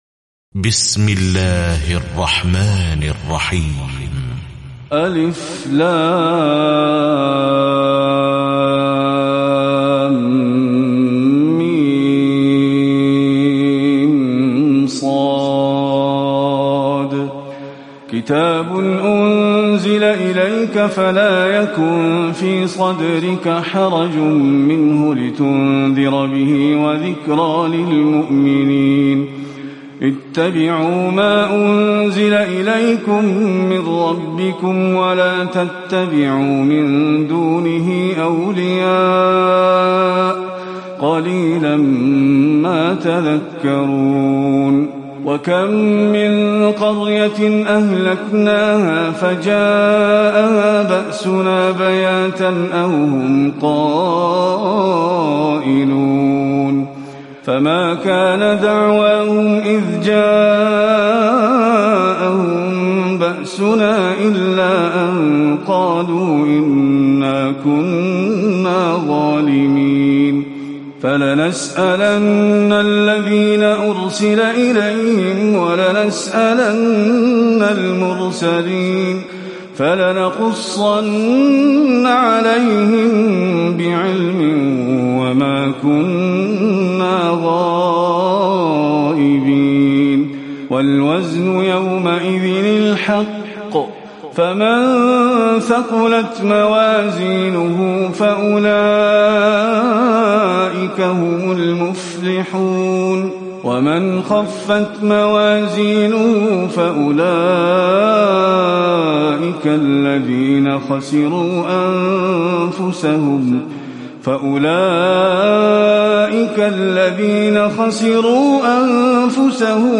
تراويح الليلة الثامنة رمضان 1438هـ من سورة الأعراف (1-84) Taraweeh 8 st night Ramadan 1438H from Surah Al-A’raf > تراويح الحرم النبوي عام 1438 🕌 > التراويح - تلاوات الحرمين